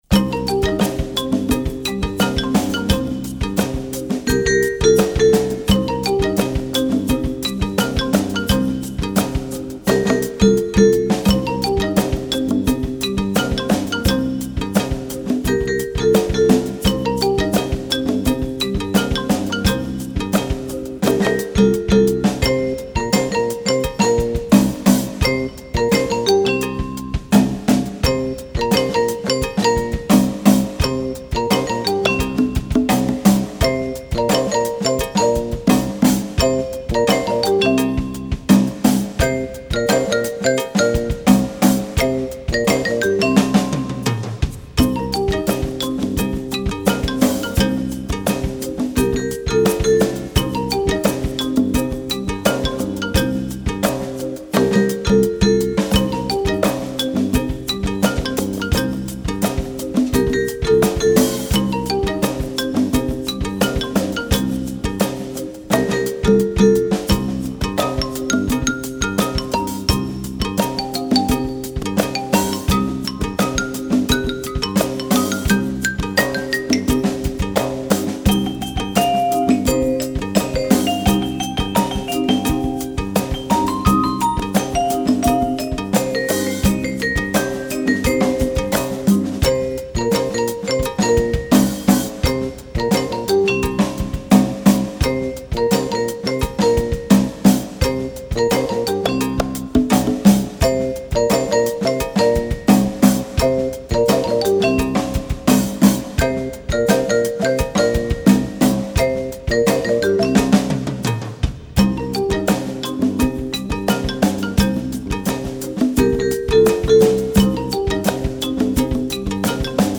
Besetzung: Instrumentalnoten für Schlagzeug/Percussion